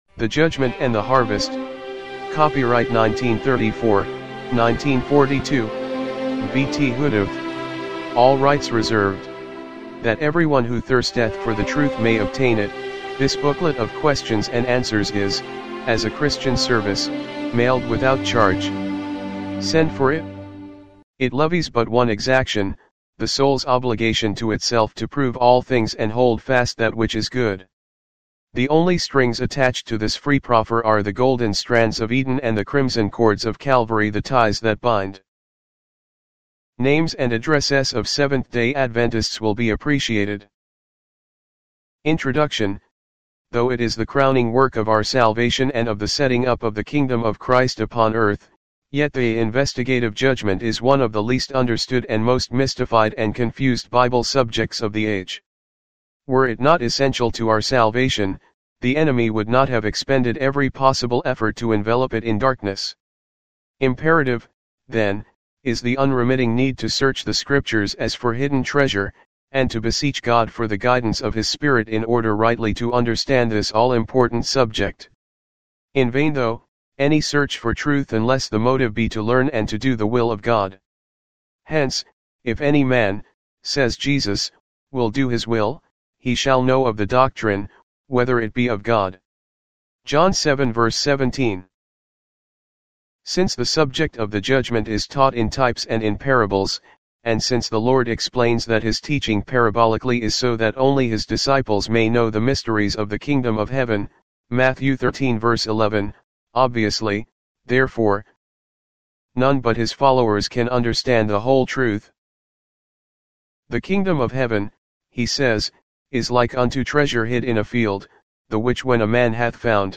the-judgment-and-the-harvest-mono.mp3